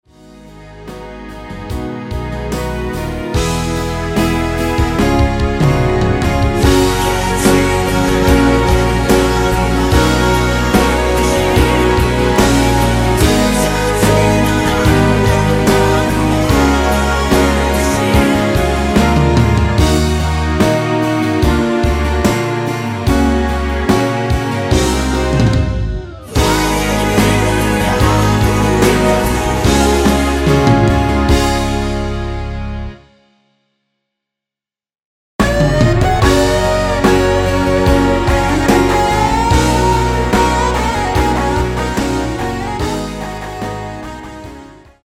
원키에서(-1)내린 코러스 포함된 MR입니다.
F#
앞부분30초, 뒷부분30초씩 편집해서 올려 드리고 있습니다.
중간에 음이 끈어지고 다시 나오는 이유는